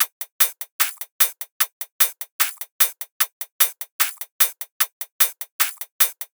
VR_top_loop_supersauce2_150.wav